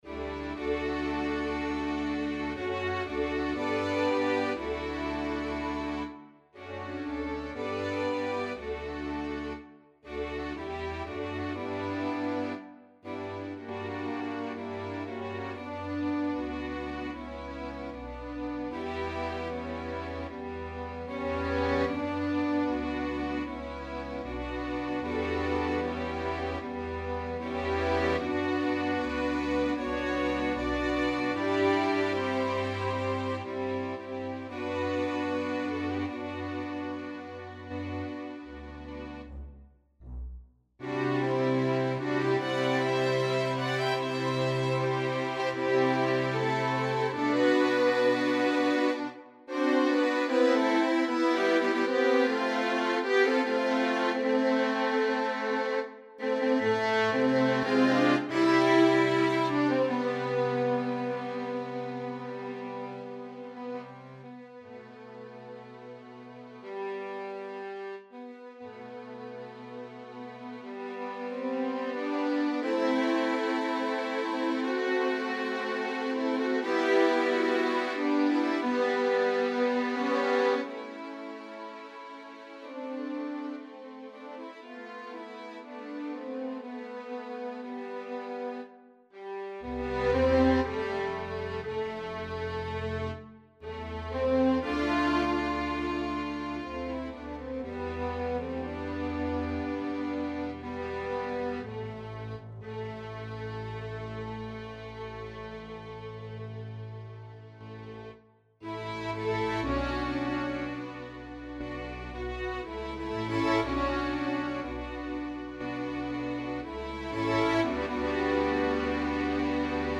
alto solo, SATB chorus divisi
This is one of my most heartfelt choral compositions.